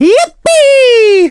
One of three voice clips from Mario in Super Mario Galaxy.
SMG_Mario_Yippee.wav